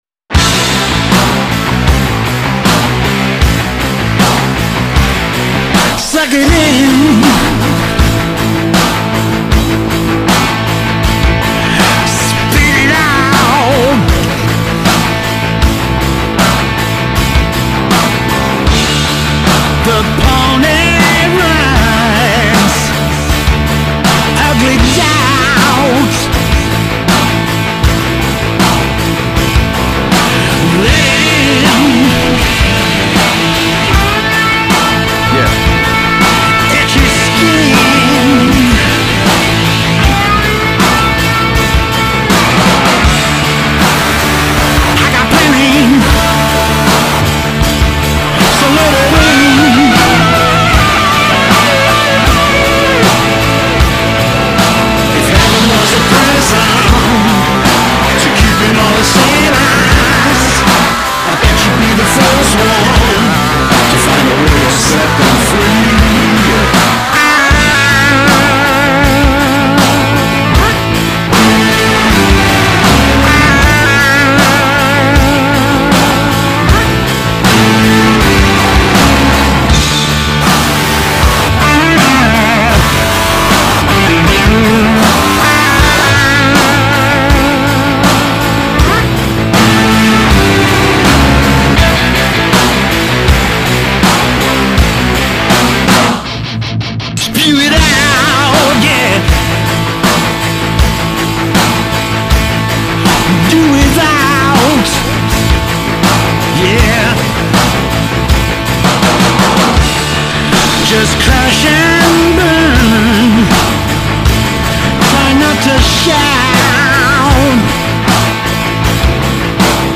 Vocals, Keyboards
Guitar
Bass
Drums